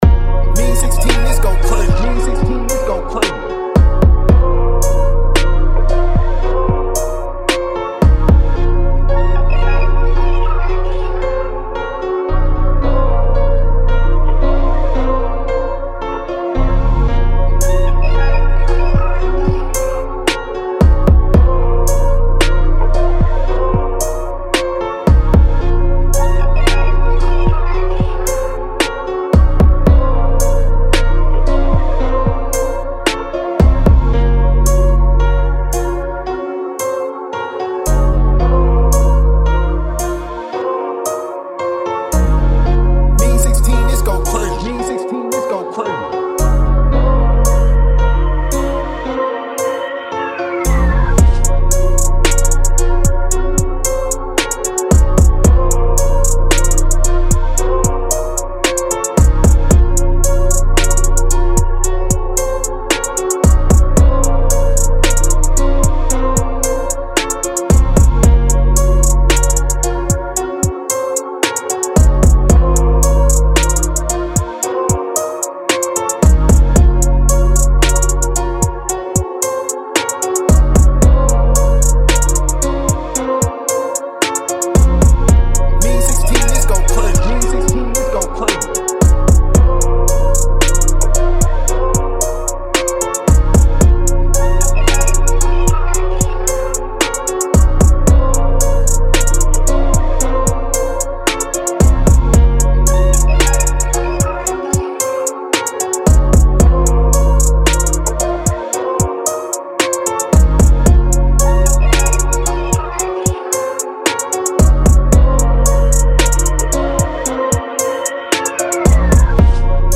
Bb-Min 112-BPM